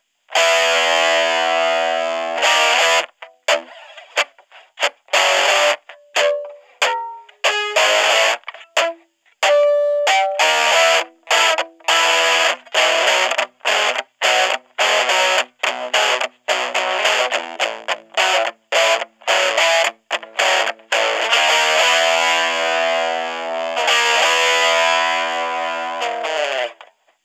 Before we get started, let me say that yes, the amp was recorded using an extension cable.
With some higher output pickups I was able to completely overdrive the little amp and it growled like crazy! To be fair it growled like a baby lion because the speaker is so small, but it growled nonetheless.